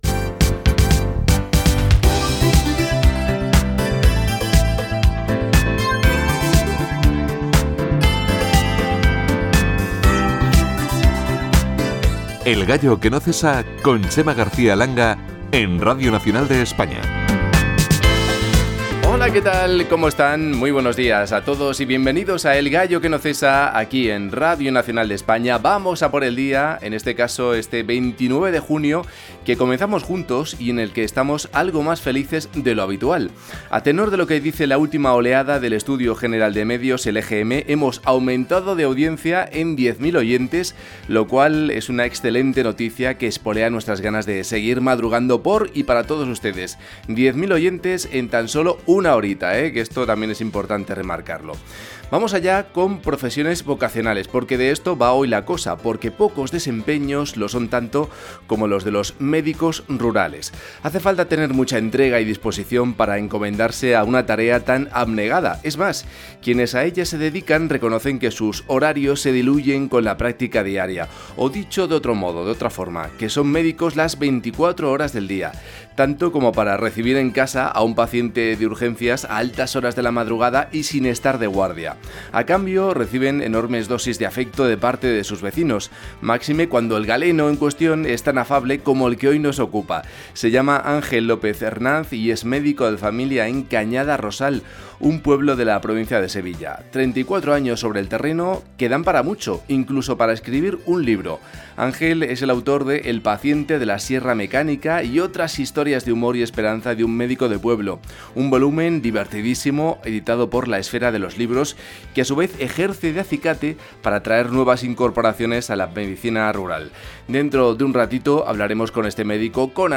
Careta del programa, presentació, bones dades d'audiència, sumari de continguts, equip, formes de contactar amb el programa
Gènere radiofònic Entreteniment